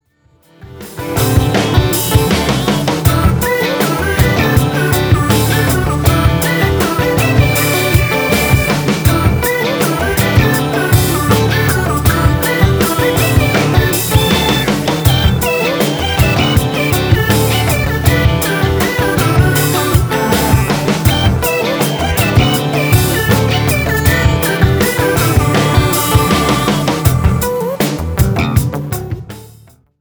FUNK  (3.09)